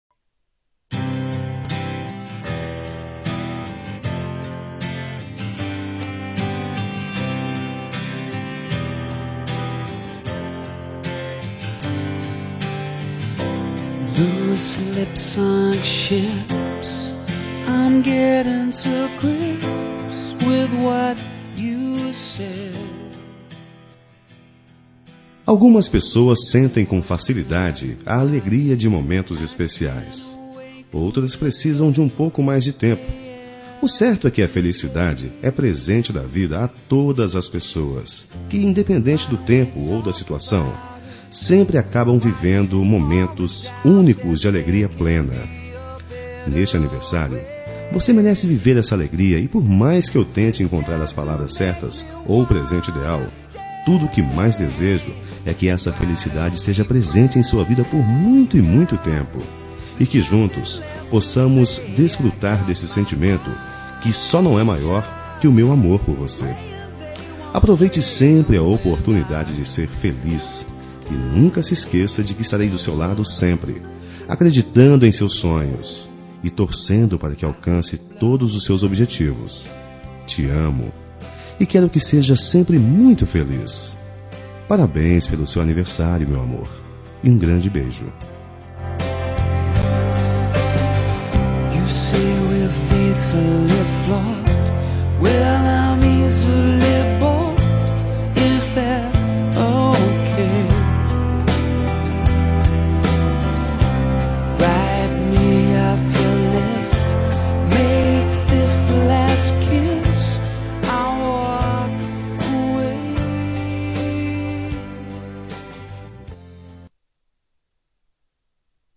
Telemensagem de Aniversário de Esposa – Voz Masculina – Cód: 1107 Linda